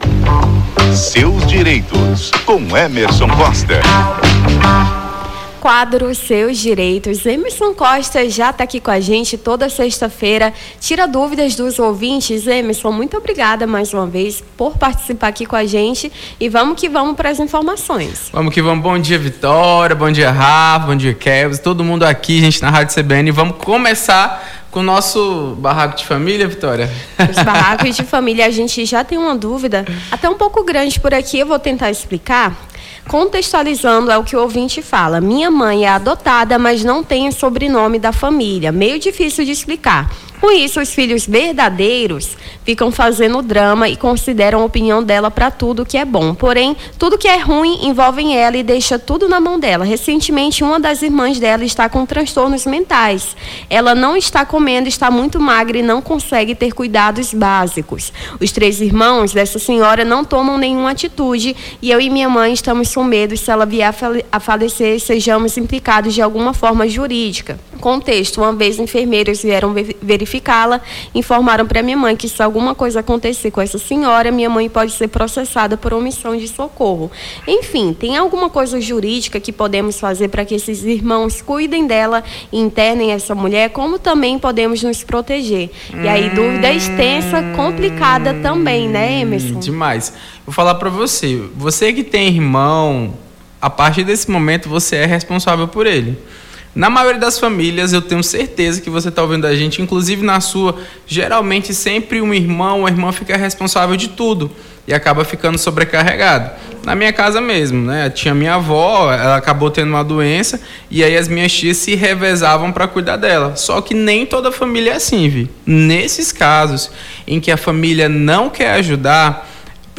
Seus Direitos: advogado esclarece dúvidas dos ouvintes sobre direito de família